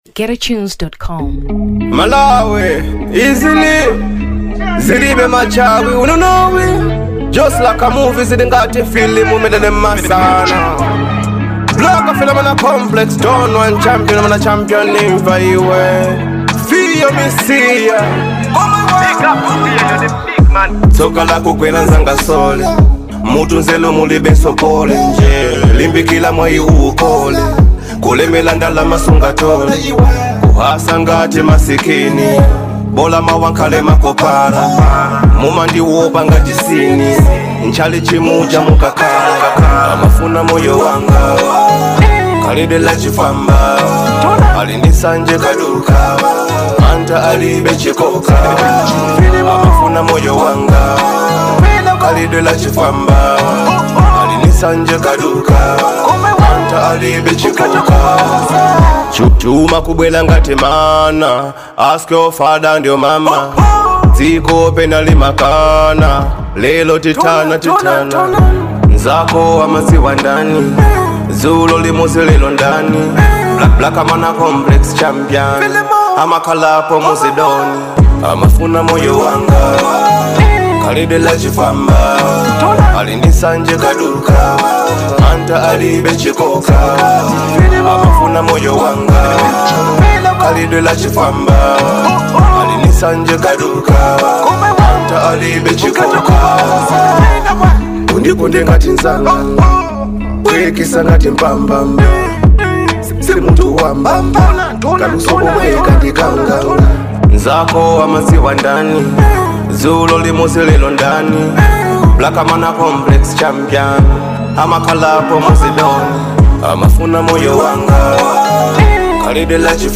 Dancehall 2023 Malawi